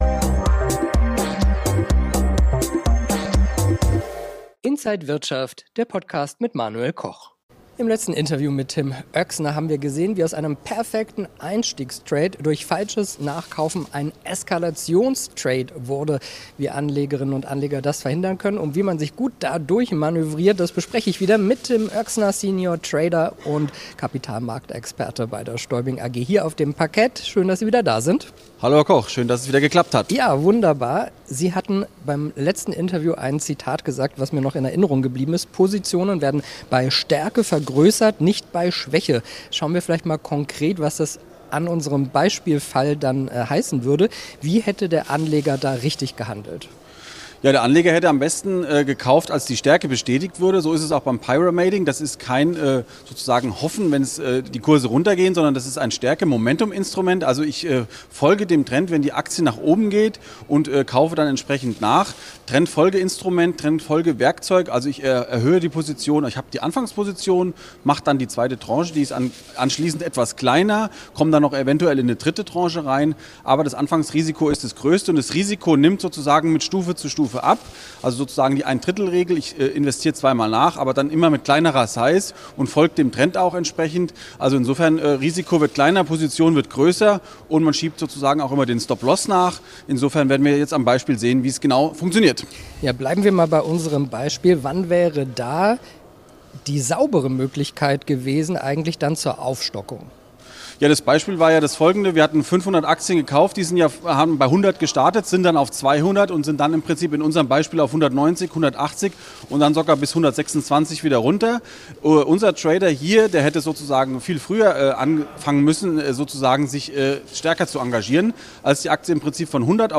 Alle Details erklärt der Experte im Interview